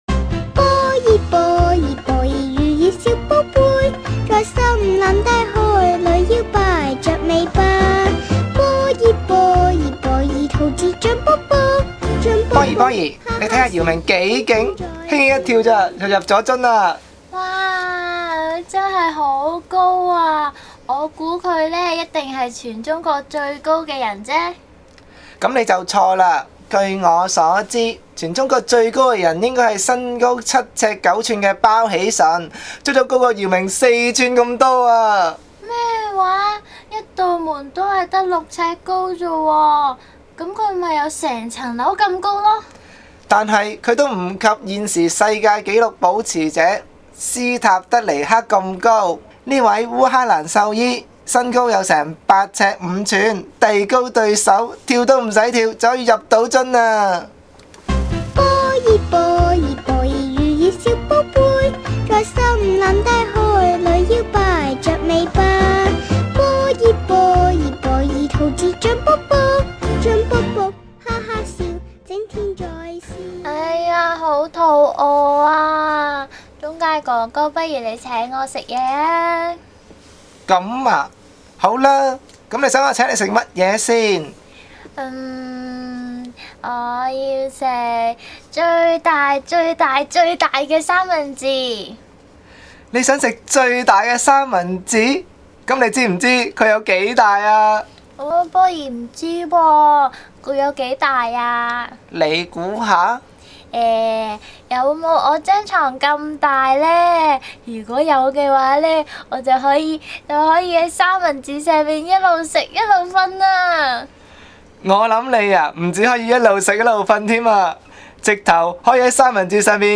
健康學習坊 主題 I - 健康飲食與運動 (小1至小2) 主題 II - 肥胖對身體的不良影響 (小3至小4) 主題 III - 建立健康生活模式 (小5至小6) 廣播劇 I - 世界之最 (~7分鐘) 廣播劇 II - 健康飲食新方向 (~6分鐘) : 問題 問題紙 廣播劇 III - 兒童糖尿個案分享(~8分鐘) 8.
07_radio-world.mp3